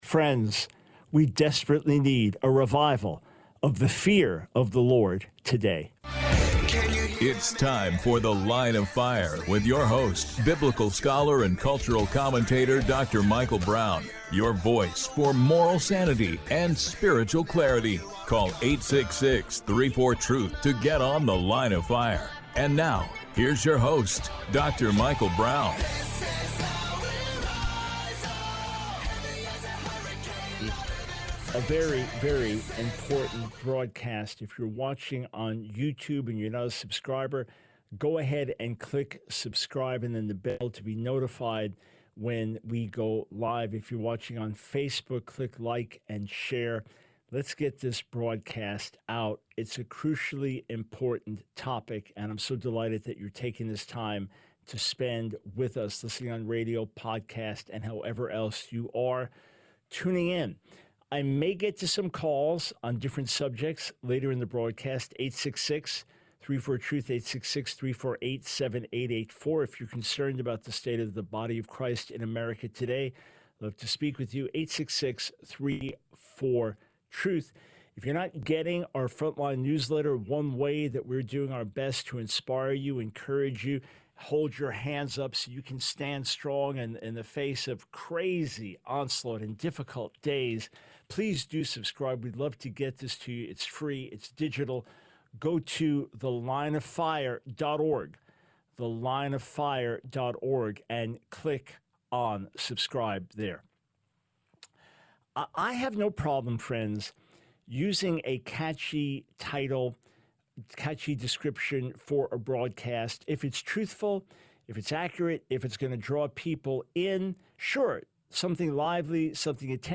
The Line of Fire Radio Broadcast for 06/19/24.